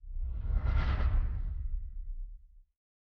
pgs/Assets/Audio/Sci-Fi Sounds/Movement/Distant Ship Pass By 7_4.wav at master
Distant Ship Pass By 7_4.wav